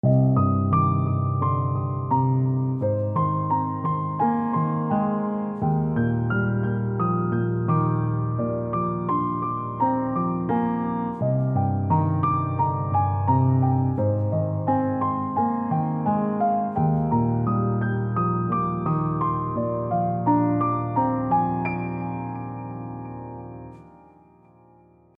Here I’m playing the classic i — VI — III — VII progression beloved by a lot of cinema composers, using the 10th-based diatonic structures in the left hand and harmonised Hanon figures Nº31 and Nº20 that are played third above the root of each chord.